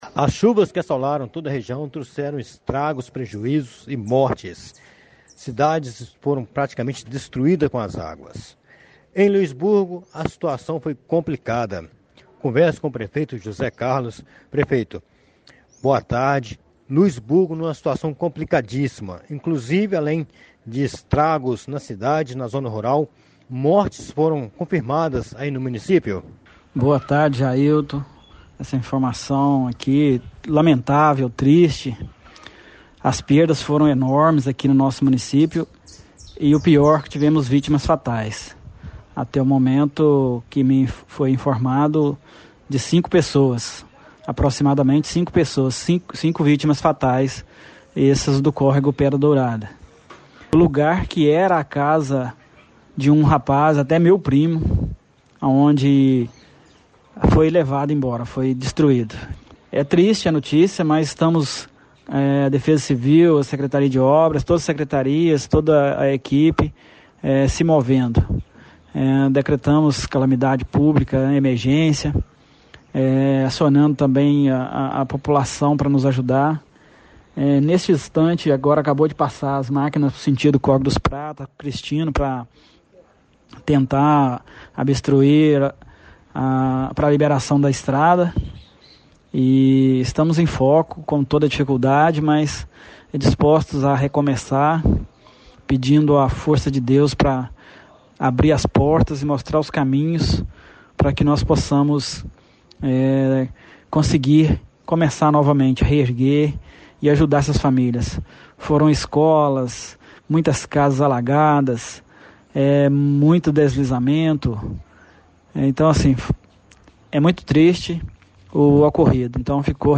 Ouça o relato do prefeito José Carlos